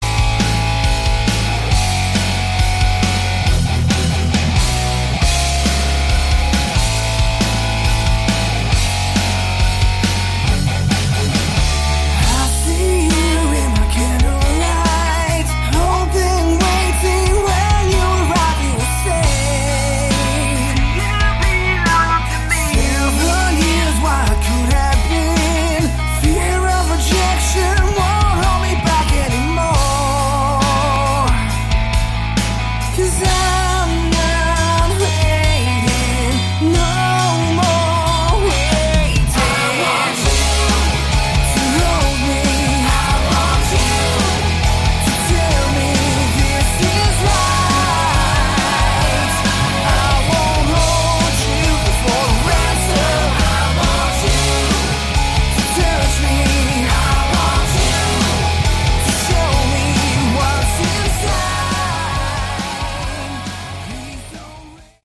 Category: Melodic Rock
keyboards, vocals
bass, vocals
drums
vocals, guitar